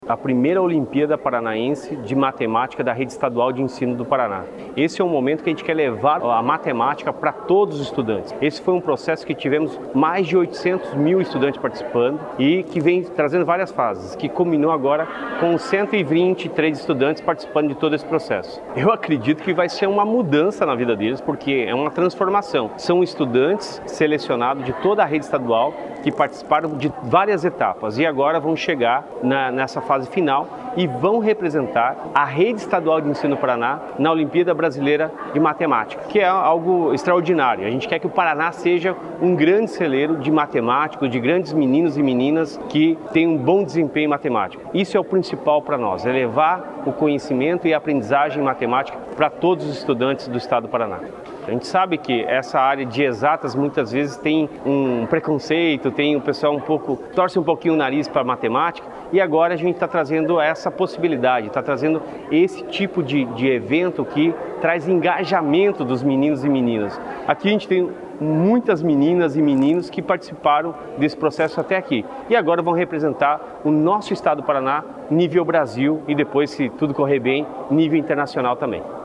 Sonora do secretário da Educação, Roni Miranda, sobre a premiação dos alunos vencedores da Omap